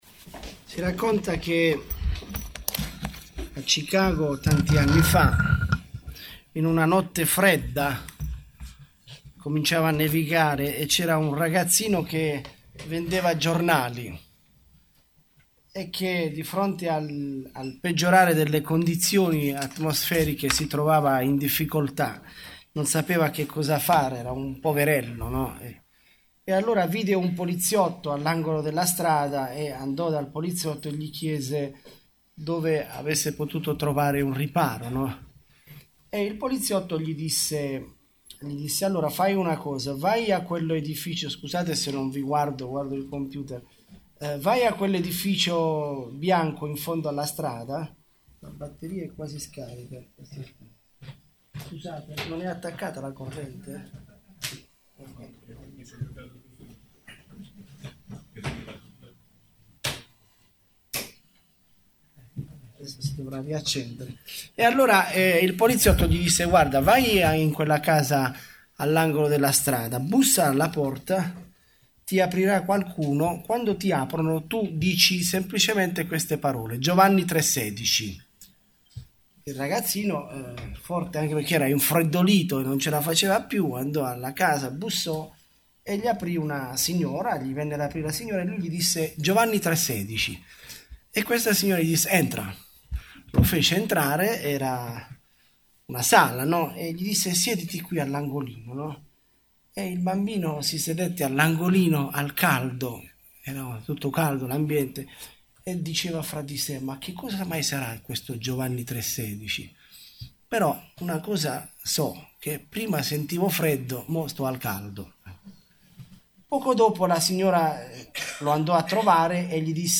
Bible Text: Matteo13:24-30 | Preacher